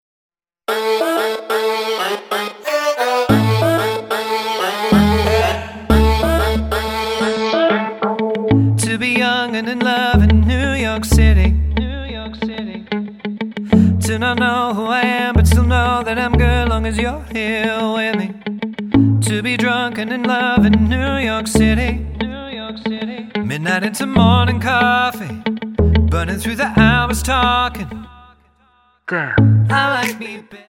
--> MP3 Demo abspielen...
Tonart:A Multifile (kein Sofortdownload.
Die besten Playbacks Instrumentals und Karaoke Versionen .